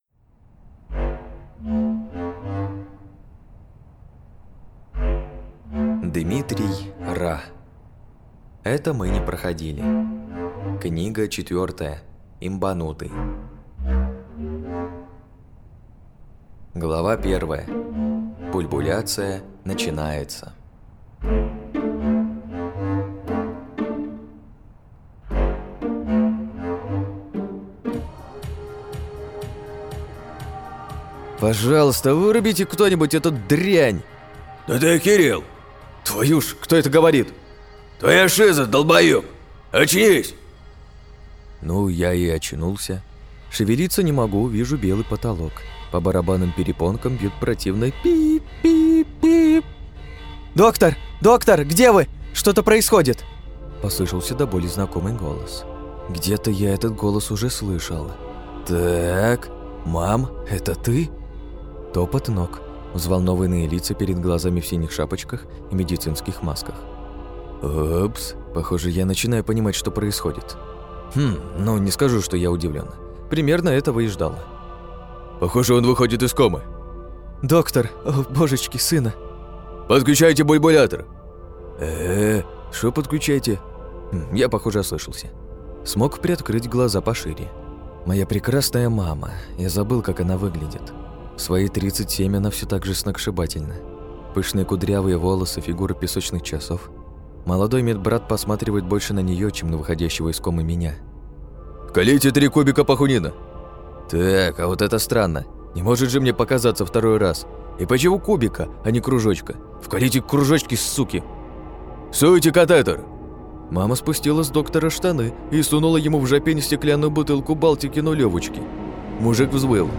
Аудиокнига Имбанутый. Том 4 | Библиотека аудиокниг